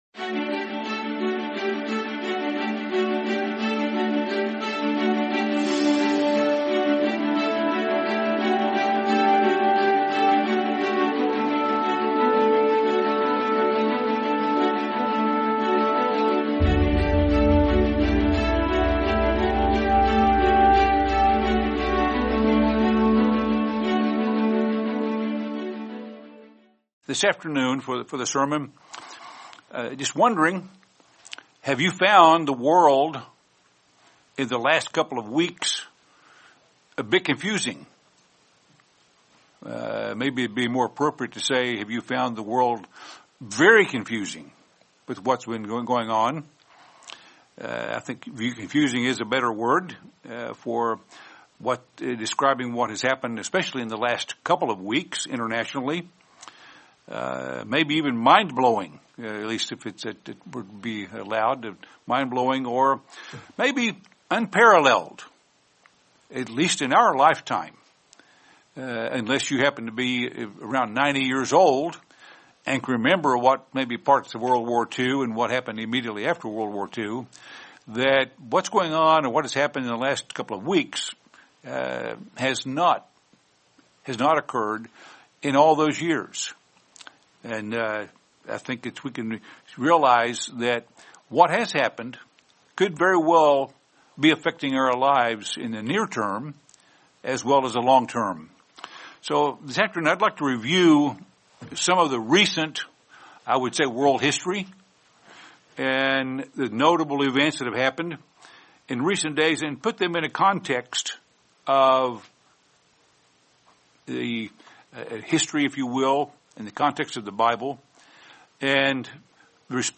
Sermon Surviving a World in Chaos